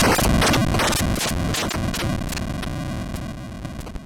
Retro Game Weapons Sound Effects – Sfx Exp Various7 – Free Music Download For Creators
Retro_Game_Weapons_Sound_Effects_-_sfx_exp_various7.mp3